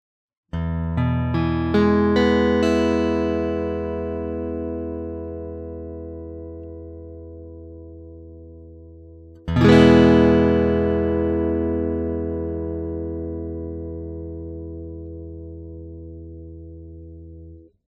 Вот некоторые звуковые файлы (ничего особенного, просто арпеджио ми мажор сопровождаемый мажорным аккордом) с моим Macassar Ebony T5 в различных положениях (от 1 до 5):
Это прямо из гитары в мой 4-х дорожечный рекордер Fostex CompactFlash.
Я думаю, что позиции 1 и 2 на Т5 дают очень акустические звуки, и я сомневаюсь, что кто-то может сказать, что они не были акустическими (по крайней мере, по сравнению с Taylor акустическими) играется через усилитель.